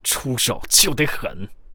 文件 文件历史 文件用途 全域文件用途 Kg_fw_03.ogg （Ogg Vorbis声音文件，长度1.7秒，109 kbps，文件大小：23 KB） 源地址:地下城与勇士游戏语音 文件历史 点击某个日期/时间查看对应时刻的文件。